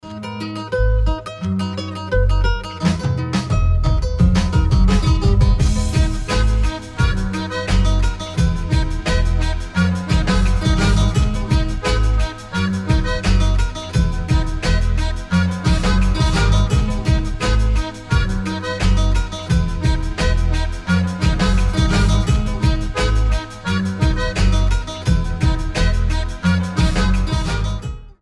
Рингтоны без слов , Гитара
Аккордеон , Акустика
Инструментальные